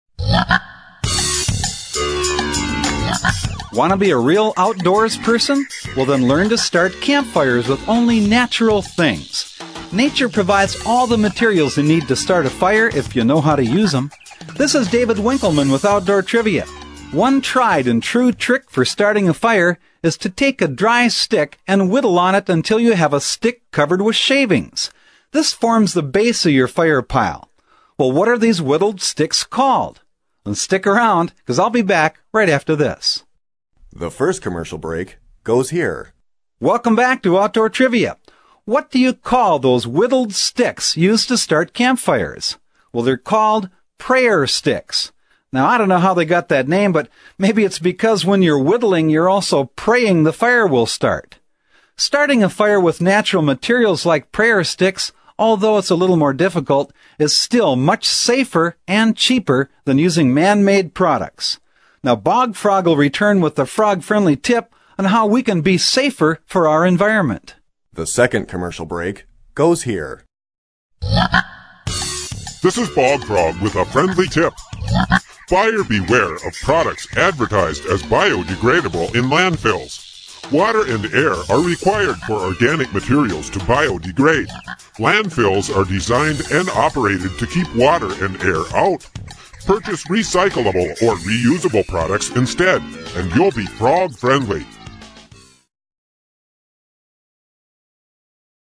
In fact, the question and answer trivia format of this program remains for many people, a most enjoyable, yet practical method of learning.
Bog Frog's voice is distinctive and memorable, while his messages remain positive and practical, giving consumers a meaningful symbol to remember.